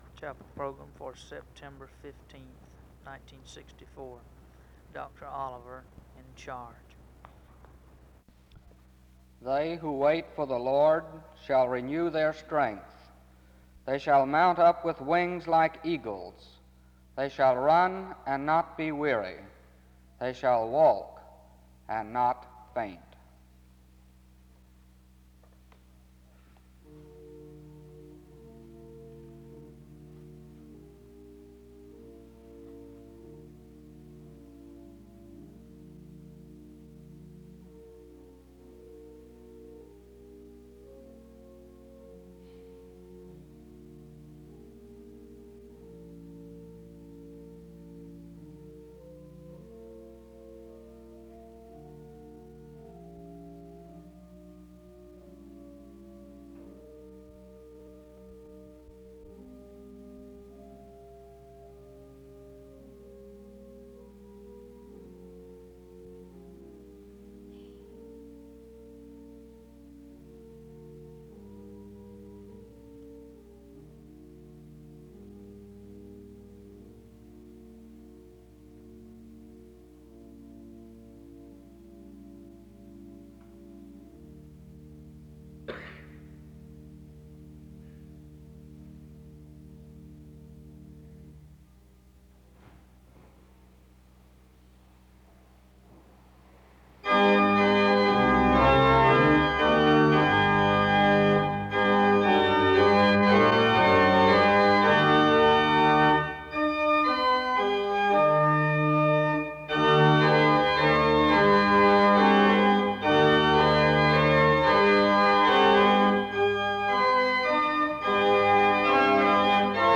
The service opens with a scripture reading and music from 0:00-4:33. There is prayer from 4:42-5:57.
SEBTS Chapel and Special Event Recordings SEBTS Chapel and Special Event Recordings